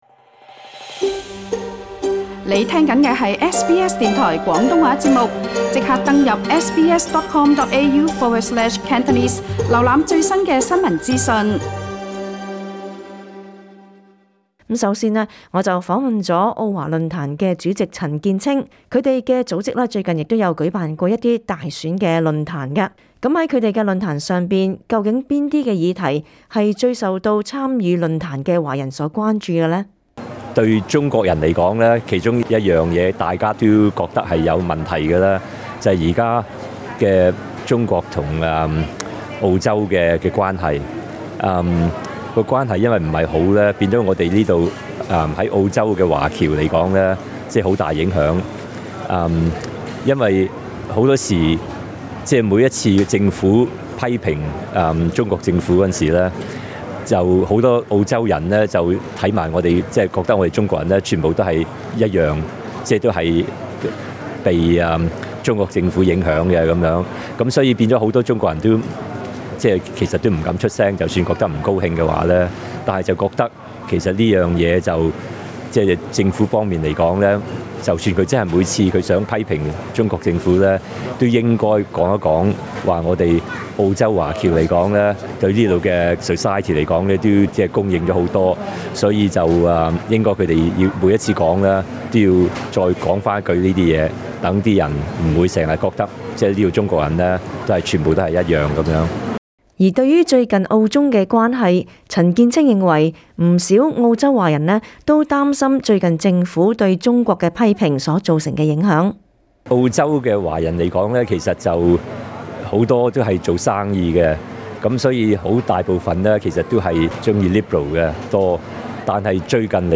下星期六（21／5）便是大選日，而上星期六（7／5），SBS電台亦特別前往雪梨Eastwood區舉行了一場大選交流會， SBS電台廣東話節目及其他語言組別亦分別與當地多位候選人及社區人士，談談他們關注的大選議題